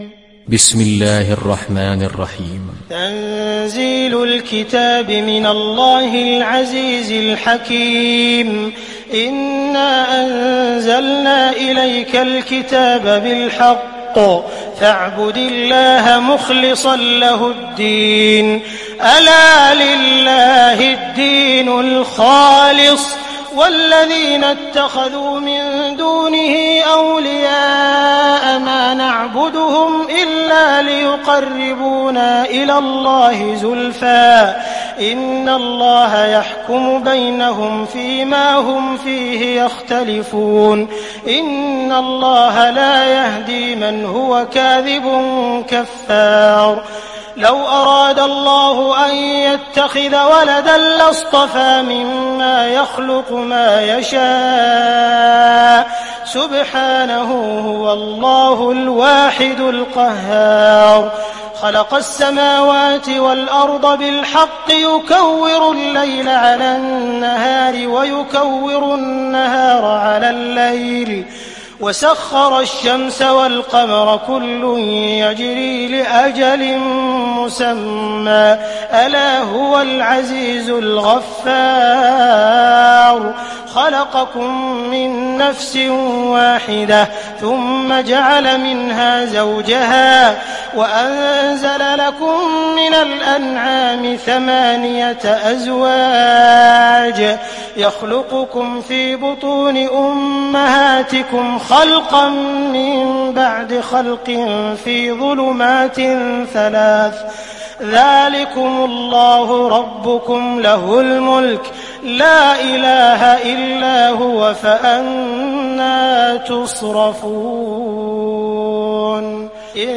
Sourate Az zumar mp3 Télécharger Abdul Rahman Al Sudais (Riwayat Hafs)